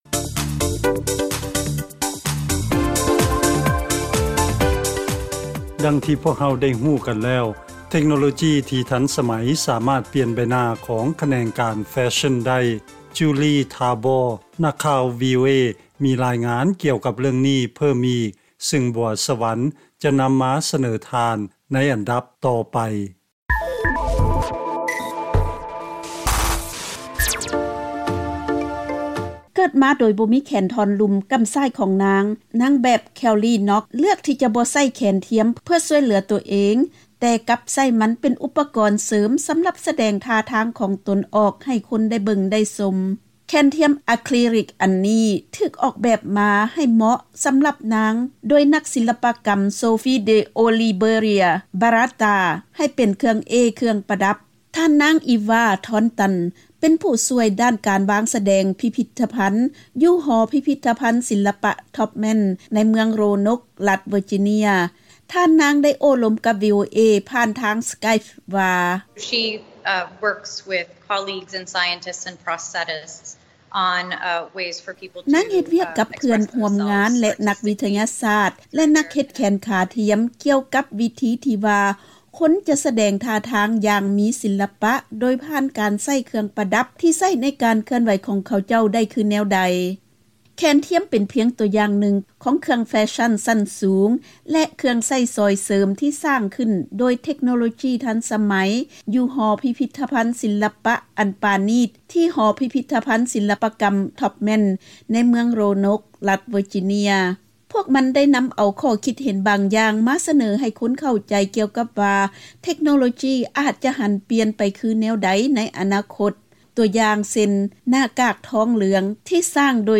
ເຊີນຟັງລາຍງານກ່ຽວກັບການໝູນໃຊ້ເທັກໂນໂລຈີທັນສະໄໝເຂົ້າໃນການປະດິດສິ່ງທີ່ເປັນແຟຊັ່ນ